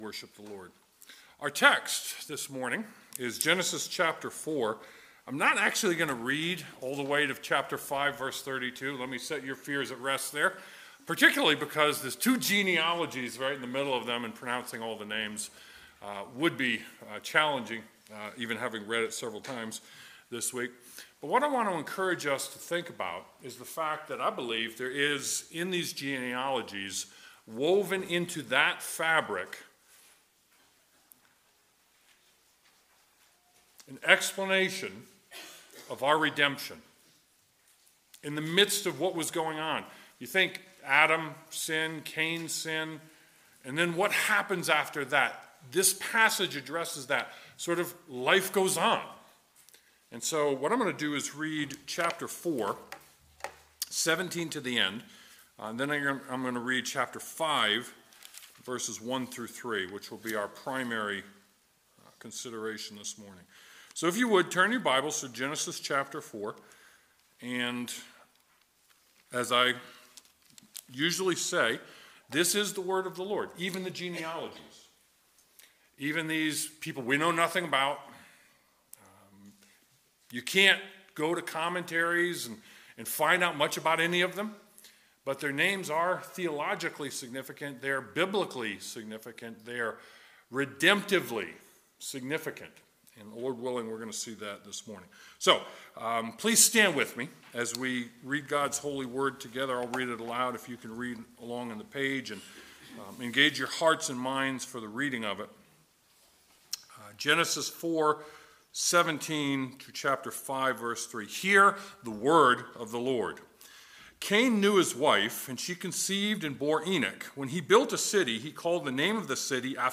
Genesis 4:17-5:32 Service Type: Sunday Morning Genesis 4:17-5:32 After Adam’s sin